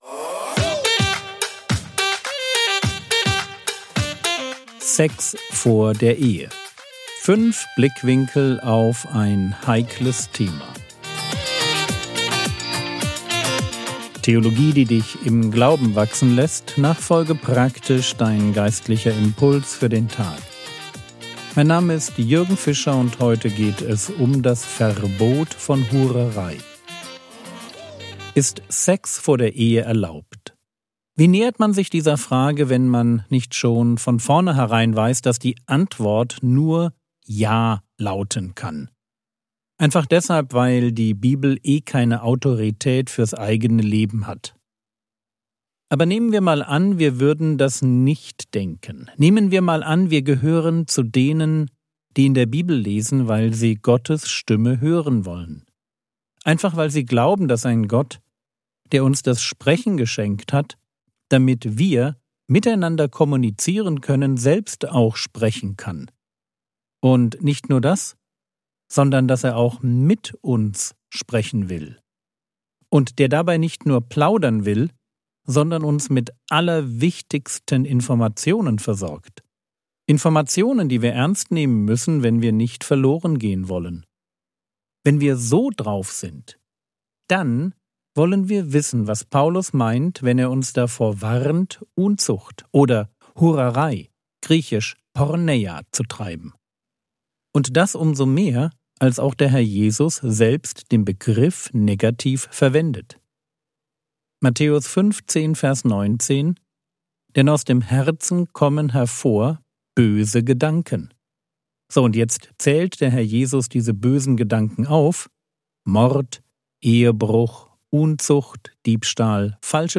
Sex vor der Ehe (2/5) ~ Frogwords Mini-Predigt Podcast